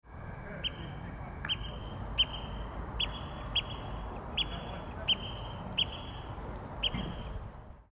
Meriharakka on reippaan äänekäs. Se ääntelee myös monin tavoin.
lyhyt) lisäksi se yhdistelee sävelaiheitaan ja improvisoi näyttäviä säkeitä.
Lentäessään meriharakka näyttää lokkimaisen vaalealta, mutta suoraviivainen ja nopea lento sekä usein lennossa kuultava kimeä vihellys paljastavat meriharakan.
meriharakan_kutsu.mp3